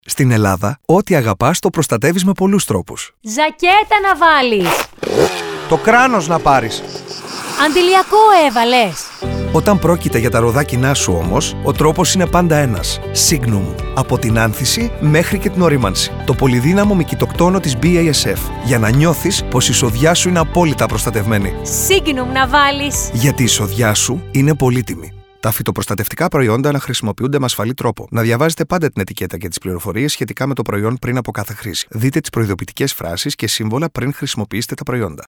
Ακούστε εδώ το ραδιοφωνικό σποτ για το Signum®.